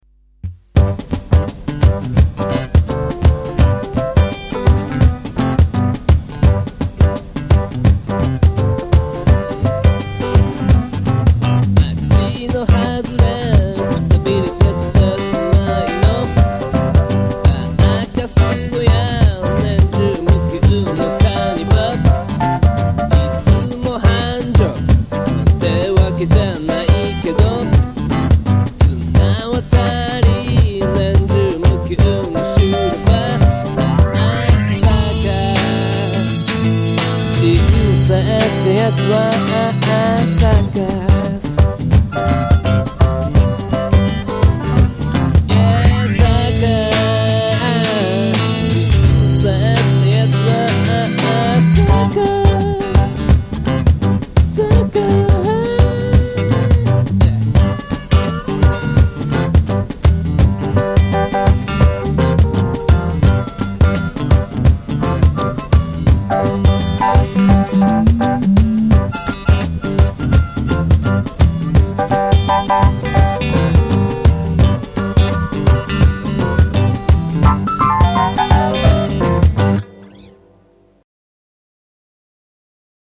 オリジナル音源のMP3公開　〜CD未収録の宅録音源〜